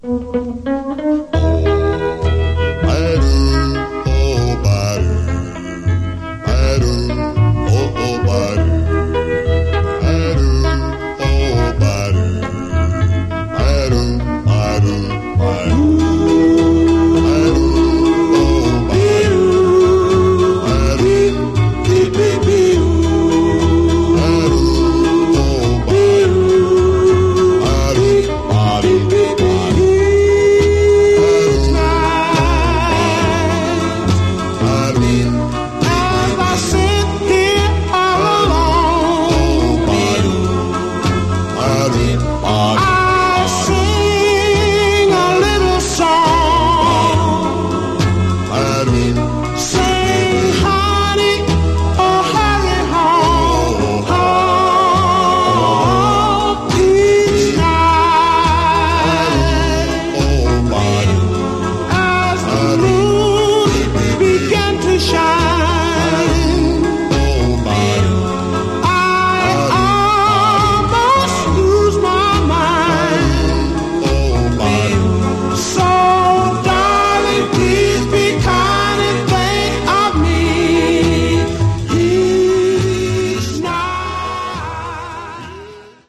Genre: Vocal Groups (Doo-Wop)
This is a heartbreakingly beautiful Doo Wop ballad.